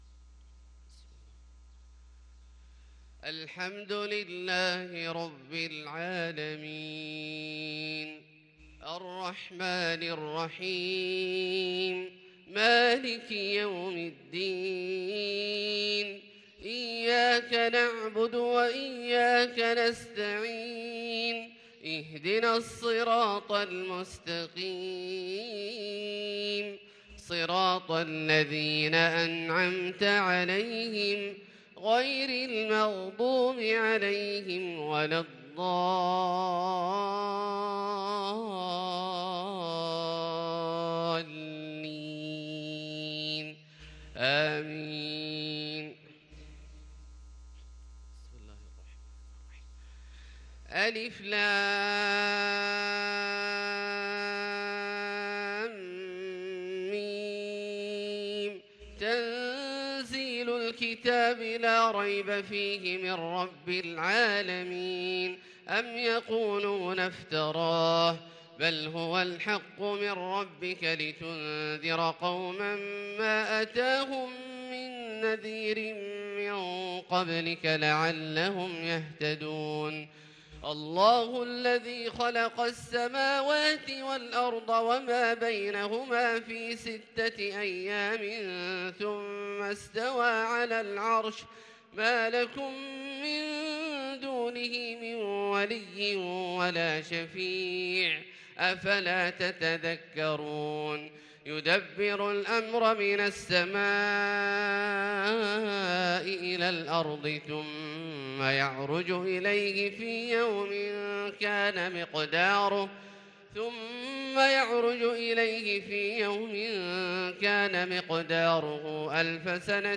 صلاة الفجر للقارئ عبدالله الجهني 25 ربيع الأول 1444 هـ